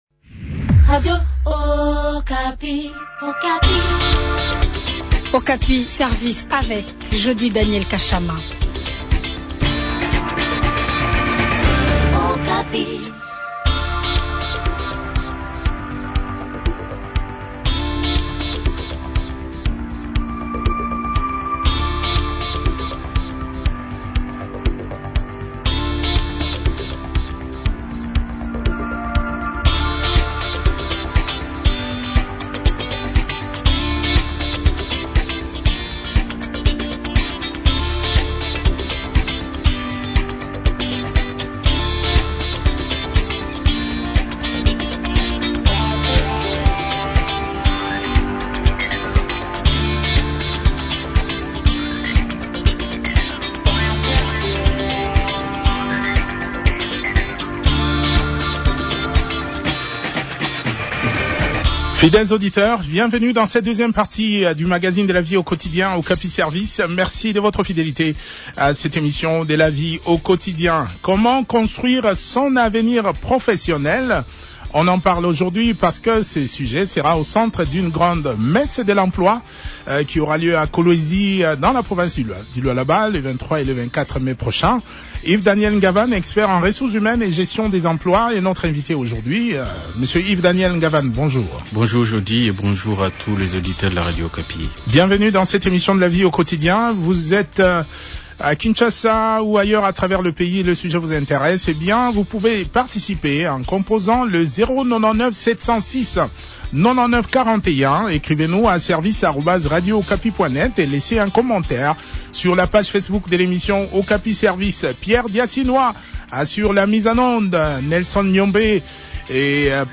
coach en organisation des entreprises.